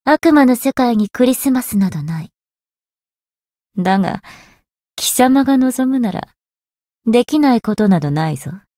灵魂潮汐-萨缇娅-圣诞节（相伴语音）.ogg